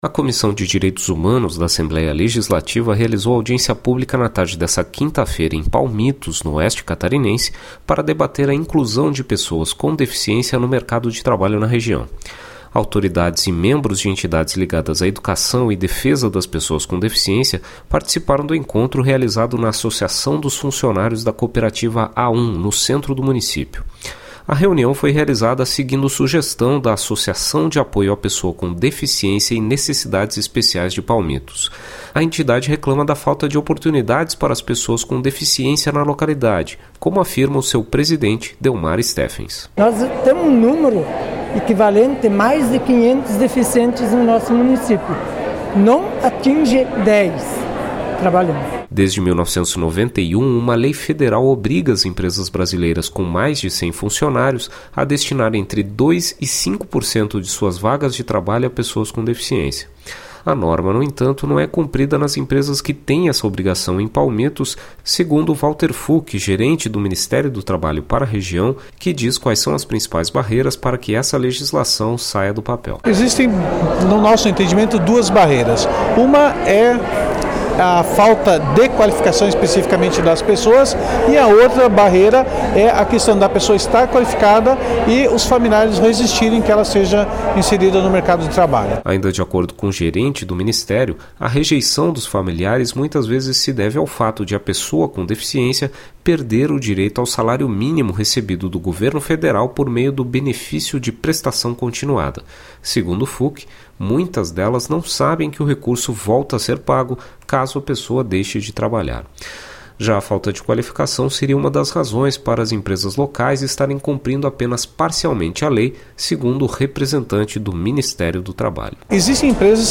Entrevistas com:
- deputada Luciane Carminatti (PT), presidente da Comissão de Direitos Humanos;